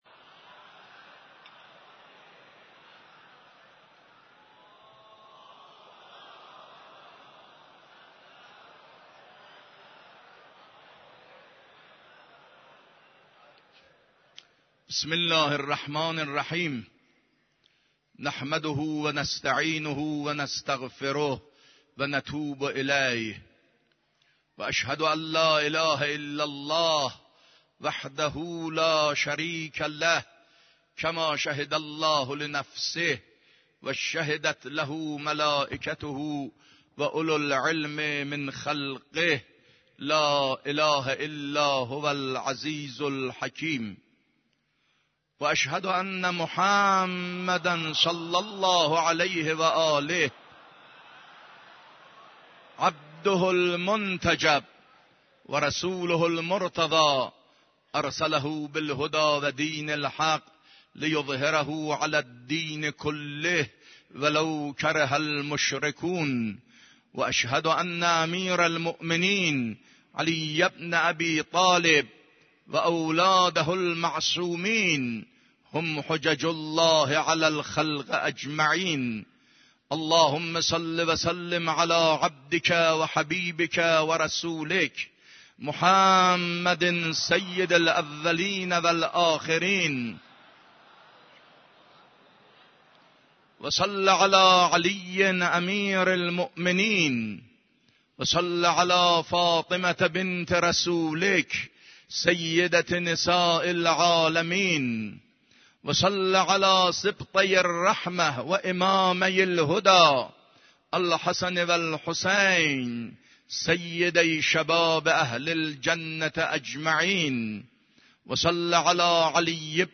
خطبه دوم نماز جمعه 26 اردیبهشت.mp3